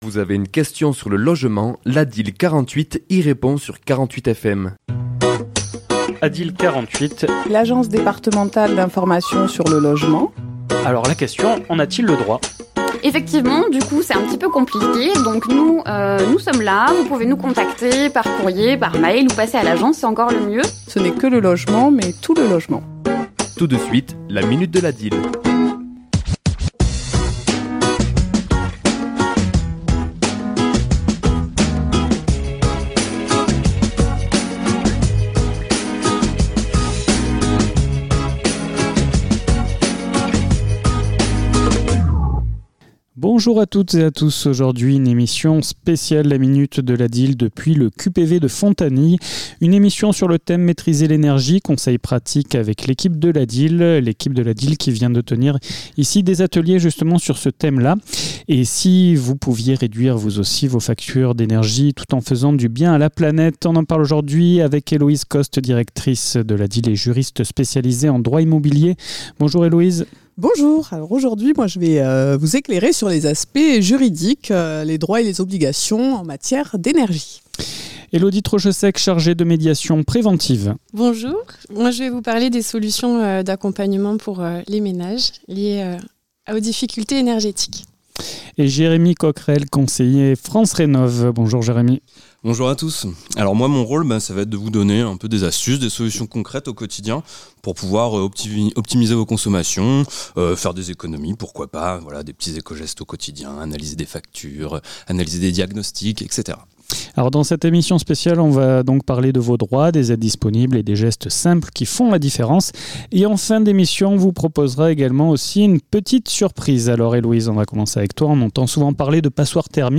Emission réalisée dans le cadre du QPV de Fontanilles à la salle Francois Mitterand,